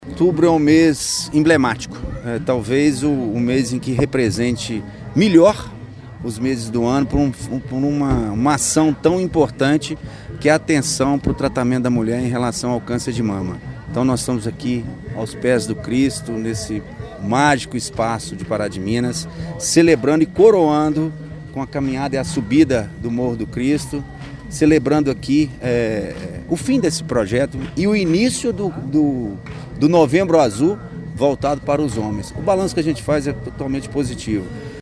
O secretário municipal de Saúde, Wagner Magesty, ressalta a importância das ações desenvolvidas neste mês de outubro, que foi coroado com esta subida até o Cristo Redentor, que marca o encerramento do Outubro Rosa e início do Novembro Azul, para conscientizar os homens sobre a importância da prevenção ao câncer de próstata: